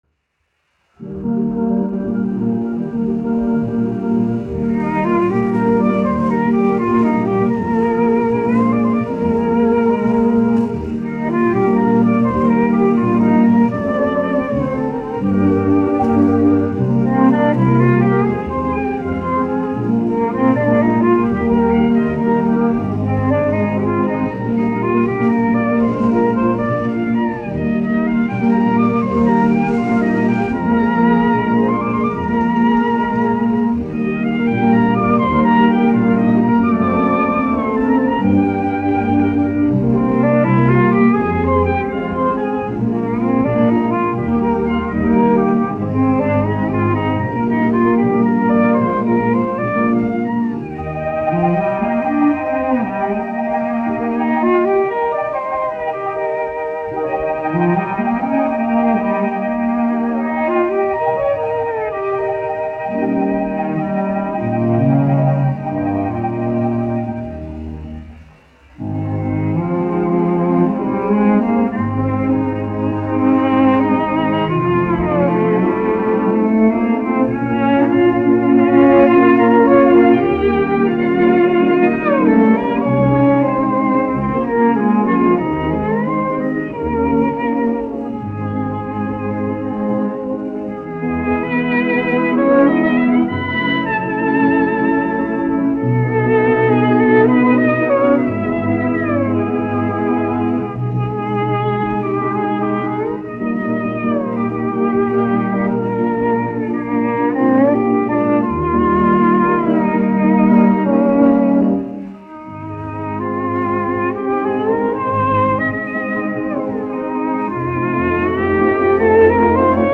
1 skpl. : analogs, 78 apgr/min, mono ; 25 cm
Orķestra mūzika
Skaņuplate